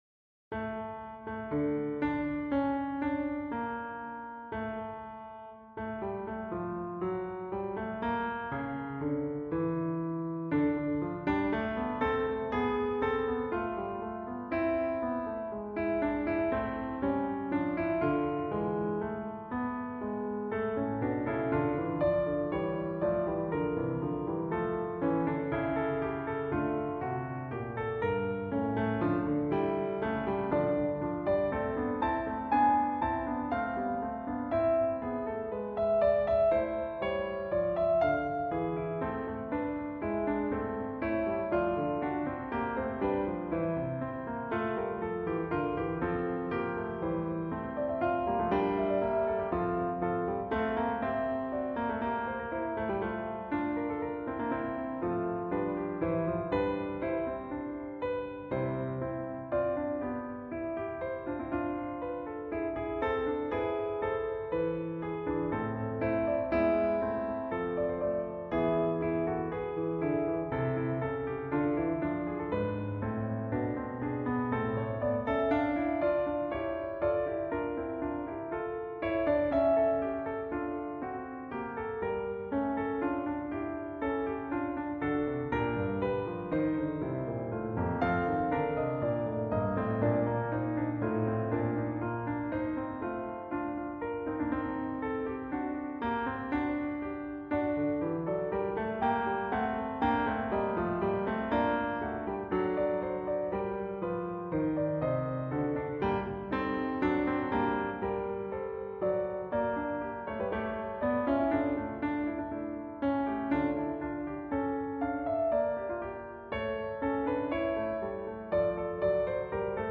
Bach's Fugue with Polyphonic and contrapuntal @ default settings
No dynamics, no slurs, no manual edits: just notes:
Pretty jaw-dropping for a computer playback.